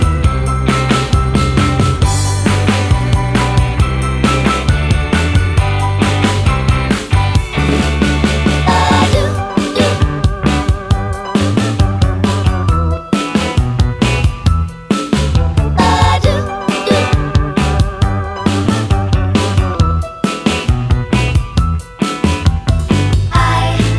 For Solo Female Pop (2000s) 3:13 Buy £1.50